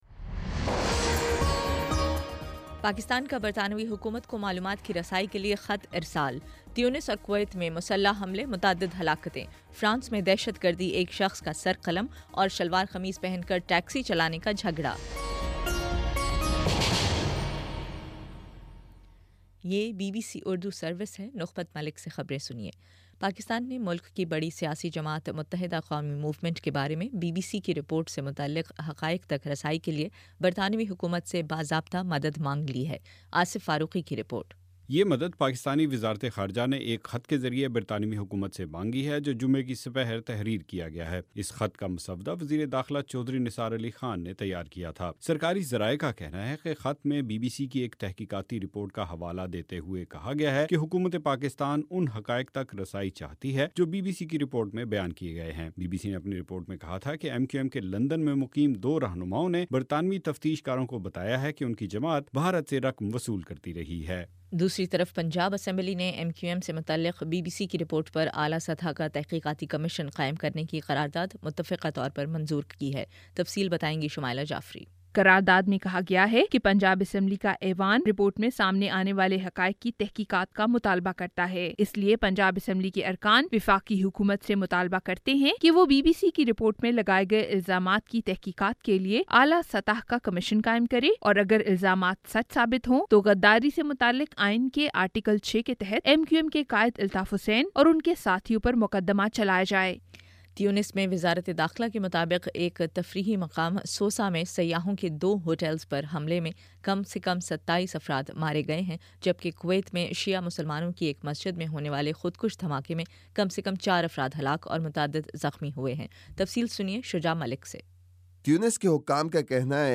جون 25: شام سات بجے کا نیوز بُلیٹن